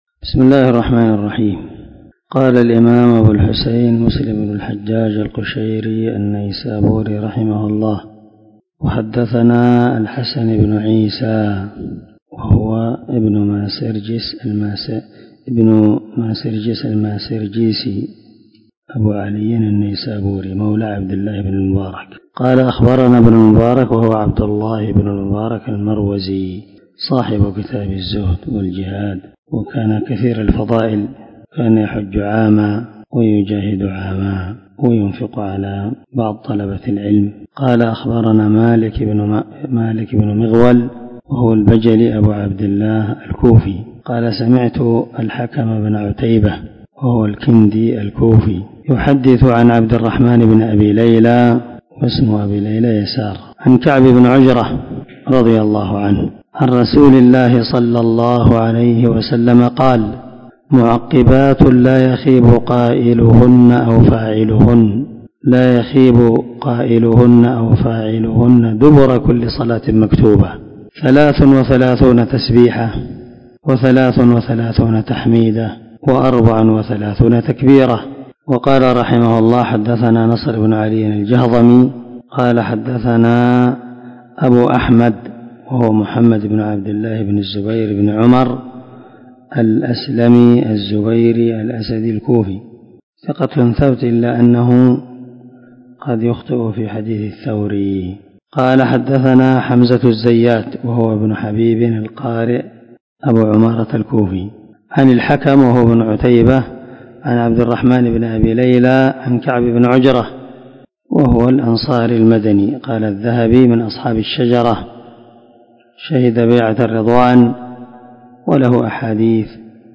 378الدرس٥٠ من شرح كتاب المساجد ومواضع الصلاة حديث رقم ( ٥٩٦ - ٥٩٧ ) من صحيح مسلم
دار الحديث- المَحاوِلة- الصبيحة.